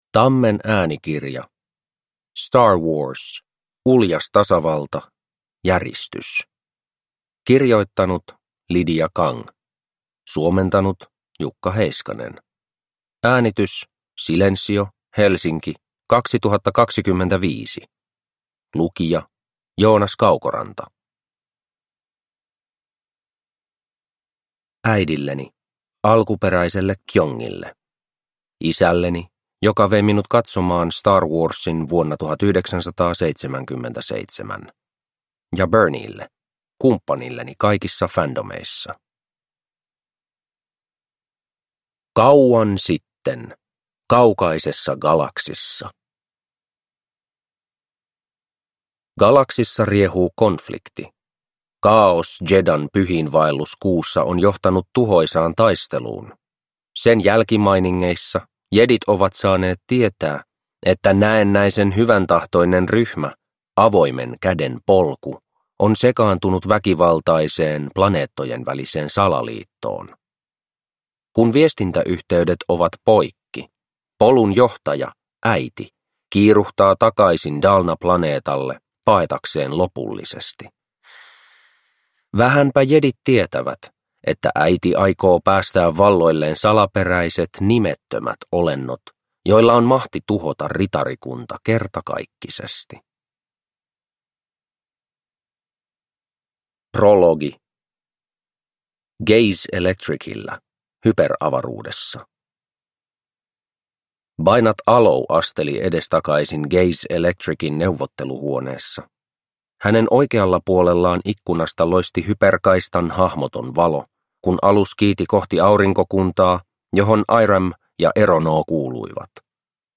Star Wars Uljas tasavalta. Järistys – Ljudbok